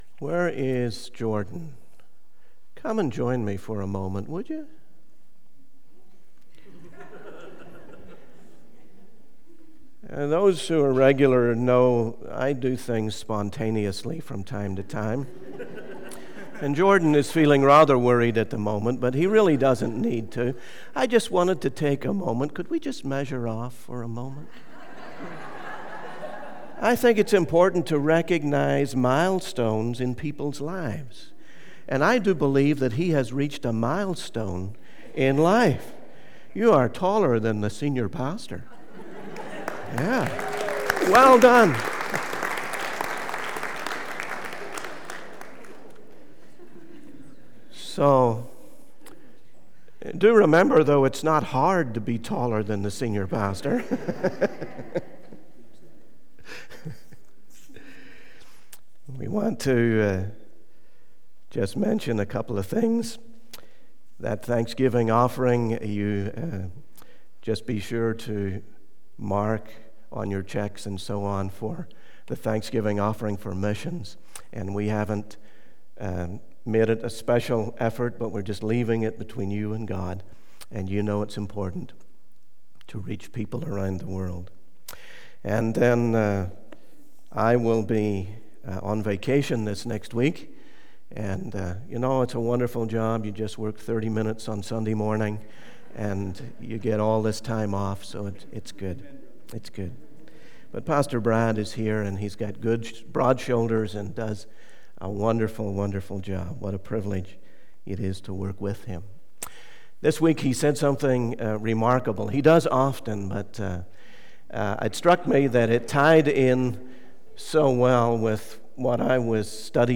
In this sermon, the preacher emphasizes the importance of taking a stand for Christ and not succumbing to worldly temptations. He highlights how Satan tries to blind people from the truth and prevent them from understanding the gospel.